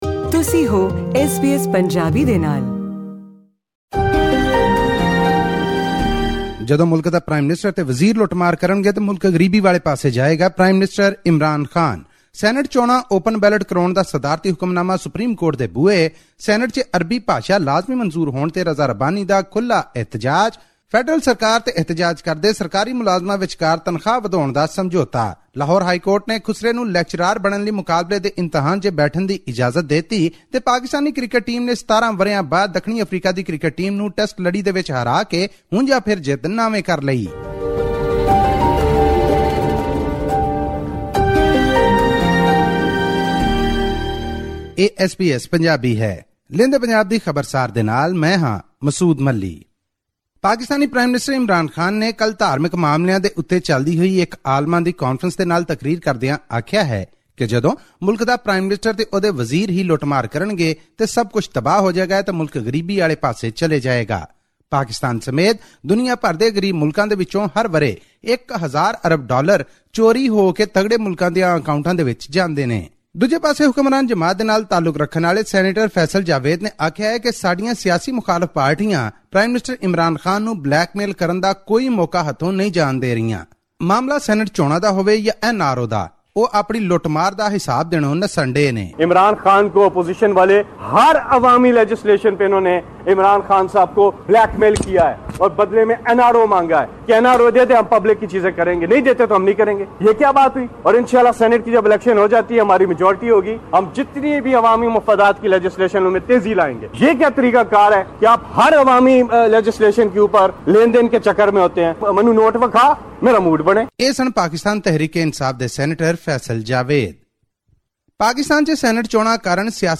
Pakistan Prime Minister Imran Khan said on Monday that nations are ruined when a prime minister and his ministers start indulging in corrupt practices. This and more in our weekly news report from Pakistan’s Punjab province.